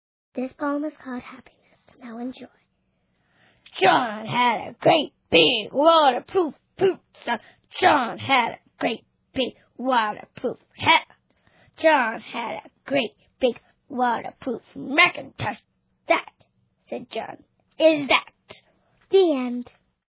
It's a collection of poems performed by kids, but For Kids By Kids is good for everybody big and little. Listeners will grin from ear to ear over the mispronounced words, the imaginative language and the expressiveness of each kid's unique voice.